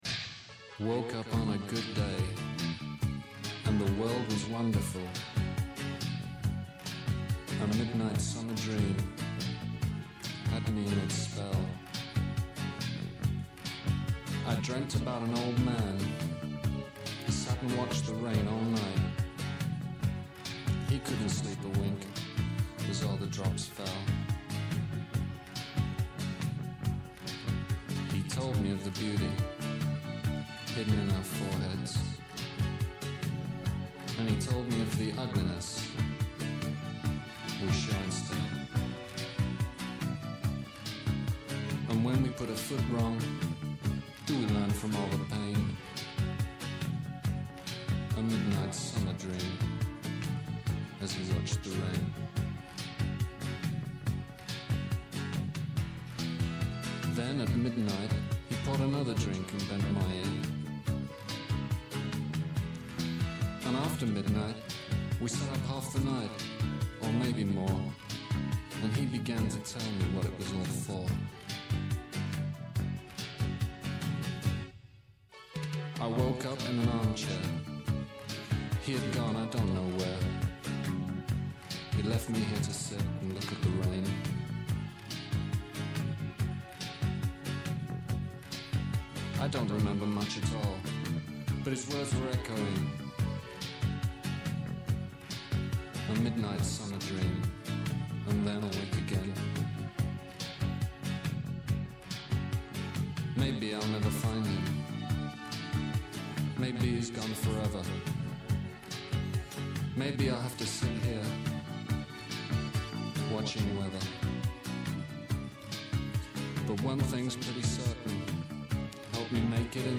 Καλεσμένοι σήμερα η Ντόρα Μπακογιάννη, βουλευτής ΝΔ, υποψήφια βουλευτής στα Χανιά